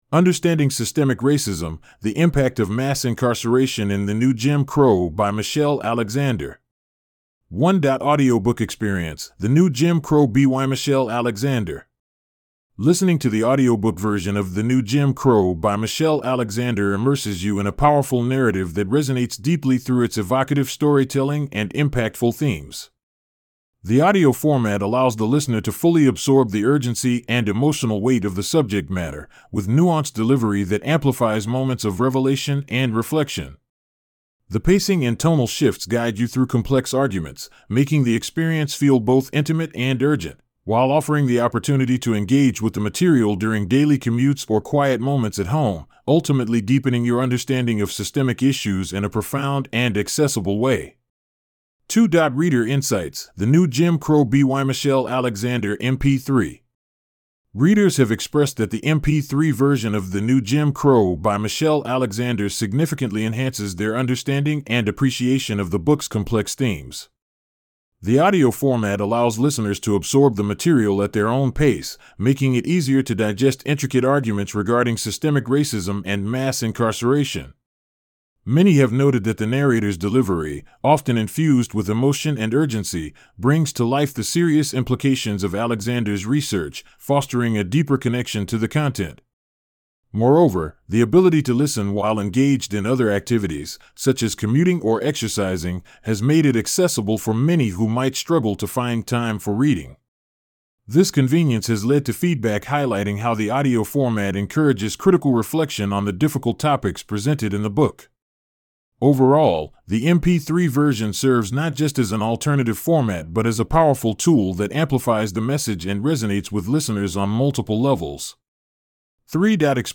1.Audiobook Experience:The New Jim Crow BYMichelle Alexander